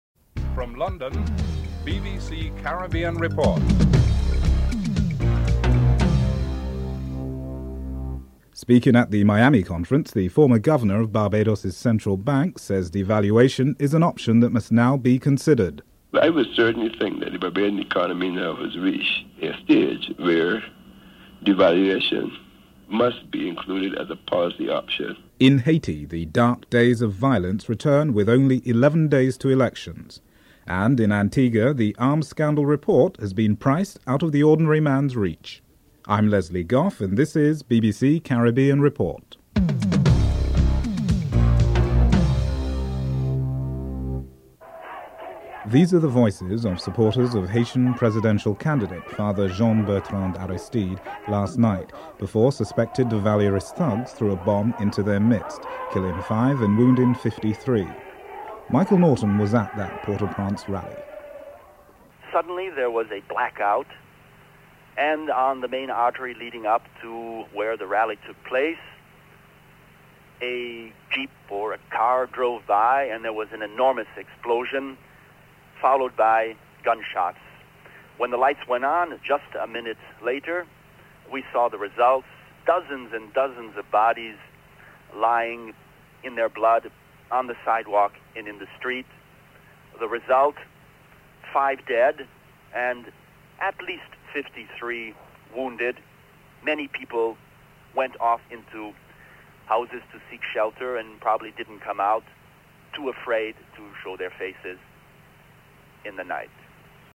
The British Broadcasting Corporation
1. Headlines (00:00-00:44)